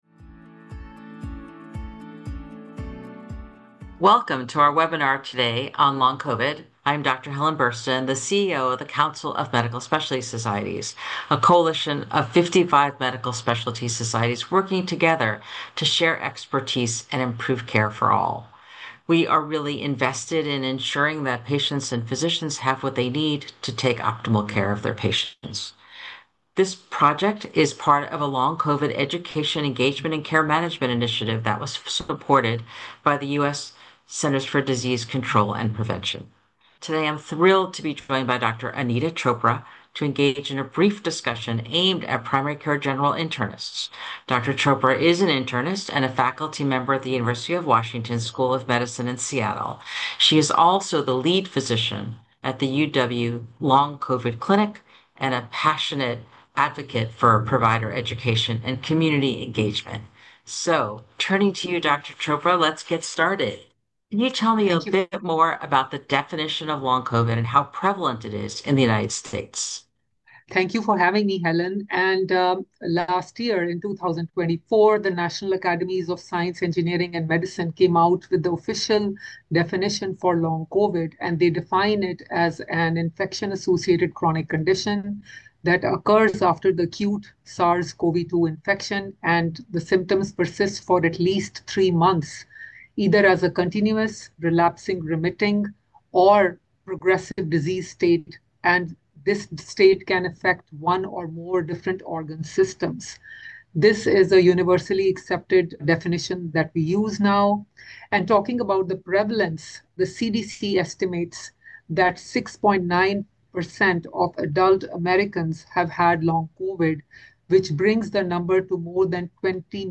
Long COVID: CMSS Interview with ACP